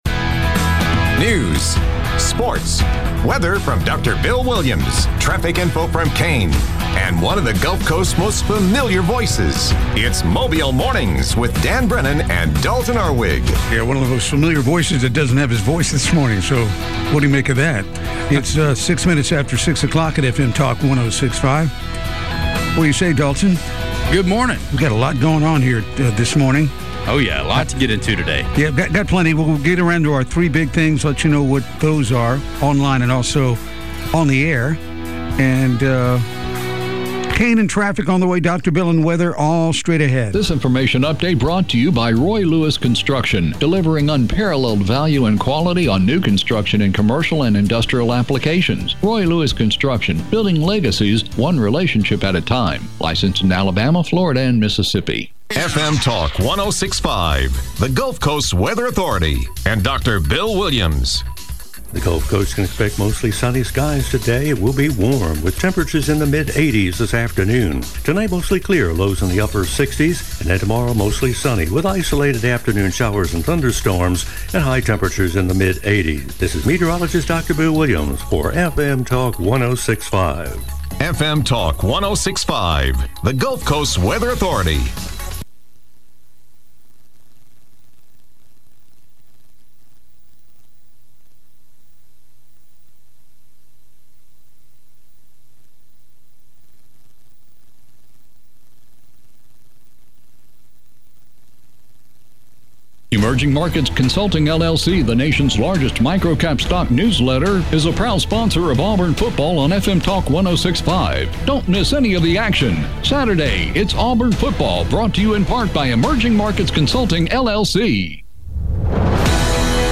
report on local news and sports.
reports on traffic conditions.